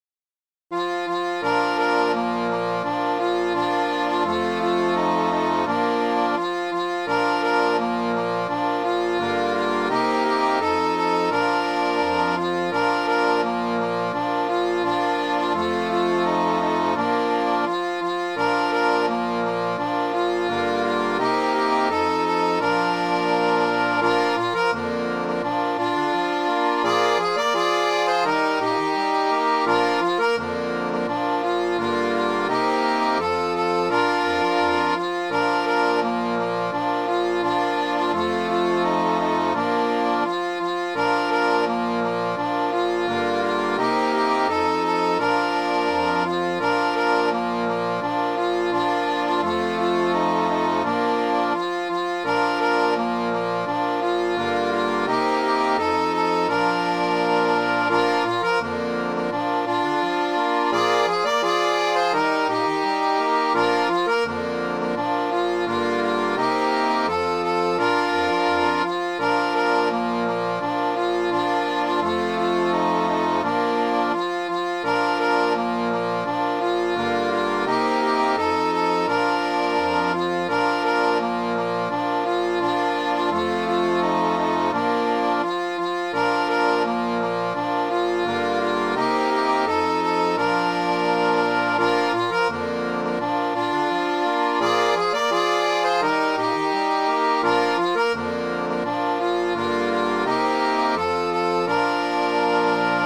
Midi File, Lyrics and Information to Wait For the Wagon